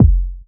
15_TrapKicks_SP_10.wav